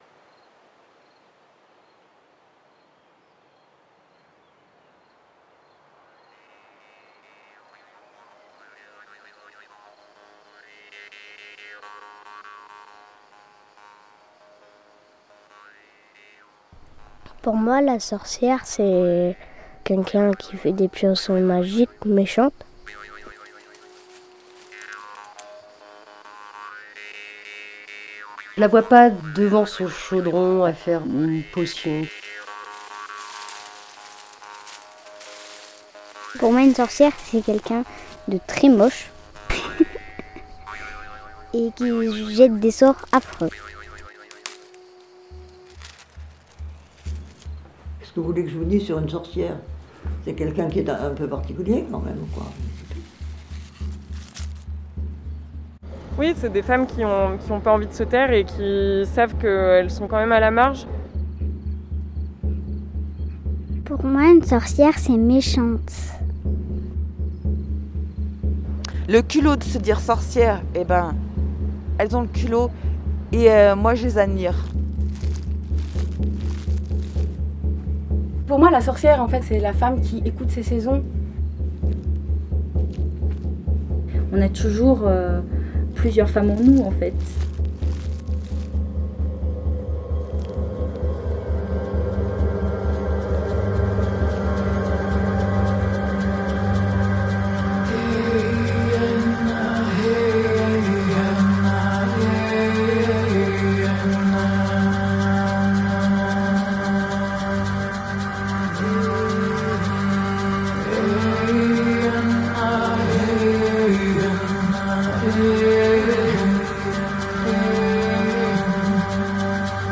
Magicienne des bois, vieille ensorceleuse ou femme culottée .. Et pour toi, c’est quoi une sorcière ?De représentations en histoires de vie, le fil de ce documentaire nous emmène à la rencontre des Sorcières du IIIème millénaire.